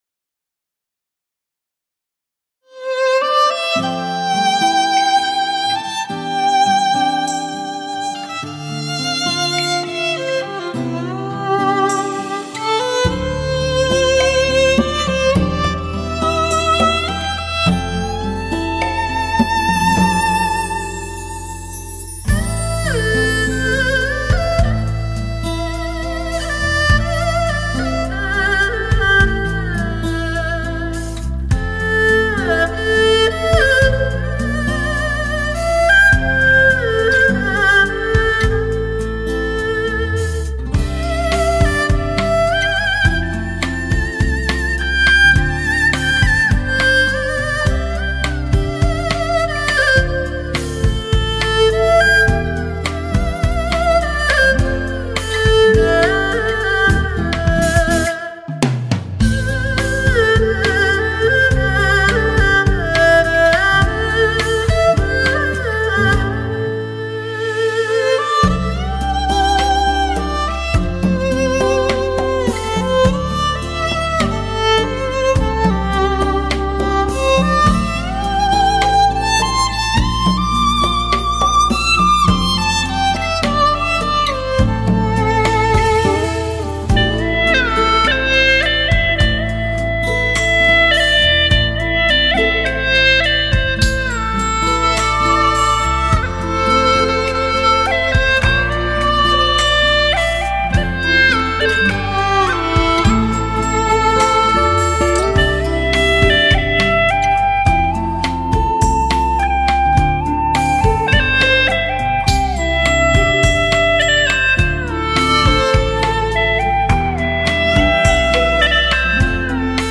世界音乐
地下溶洞HI-FI现场收音
二胡音乐天碟
天然声场处理无法比拟 犹如身临其境的质感